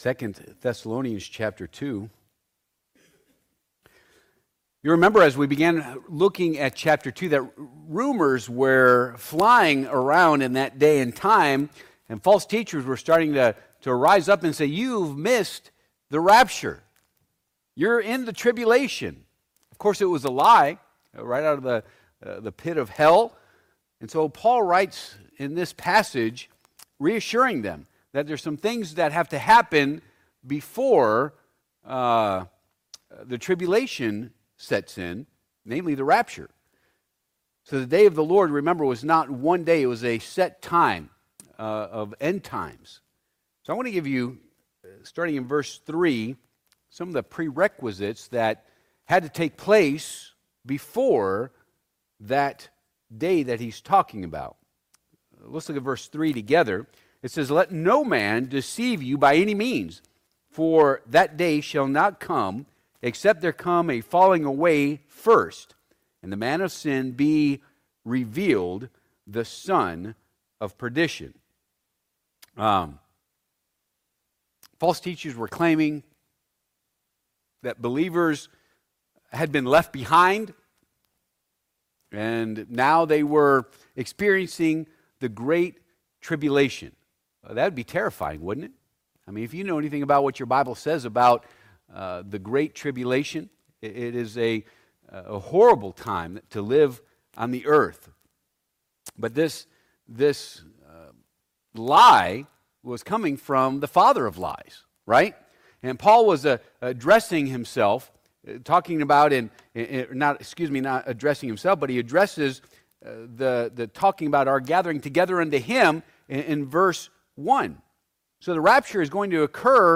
2 Thessalonians Passage: 2 Thess. 2:3 Service Type: Midweek Service « Jesus IS the Answer!